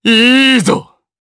DarkKasel-Vox_Happy4_jp.wav